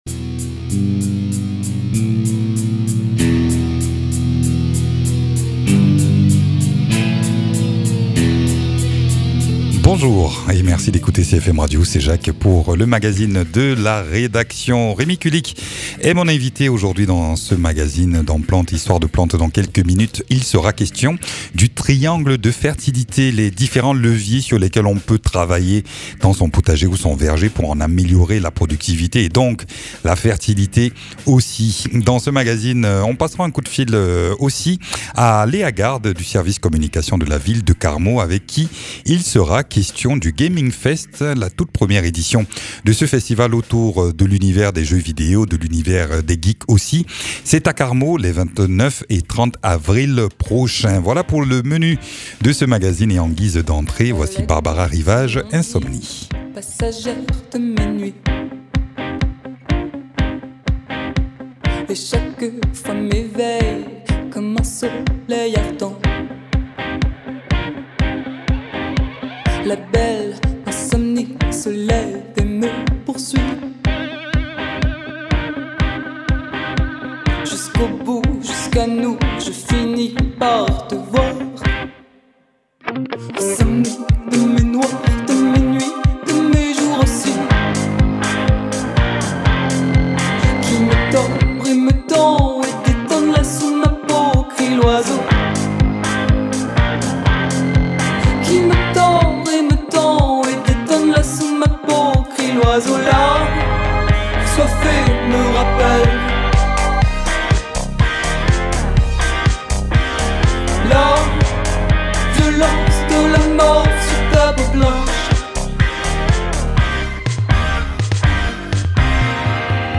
jardinier permaculteur.